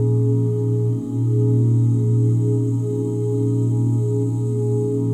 OOHA#SUS13.wav